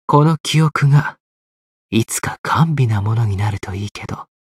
觉醒语音 この記憶が、いつか甘美なものになるといいけど 媒体文件:missionchara_voice_237.mp3